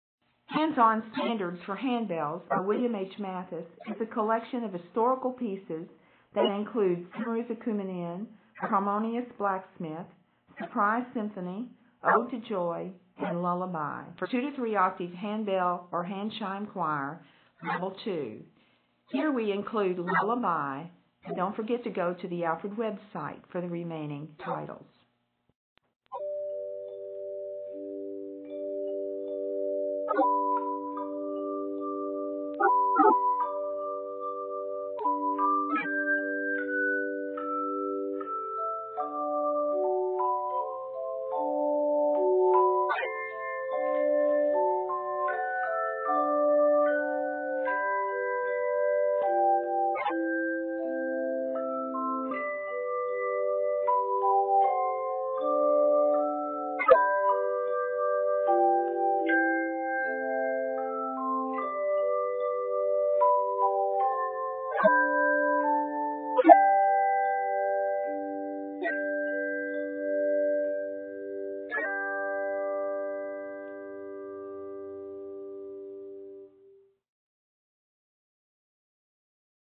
Octaves: 2-3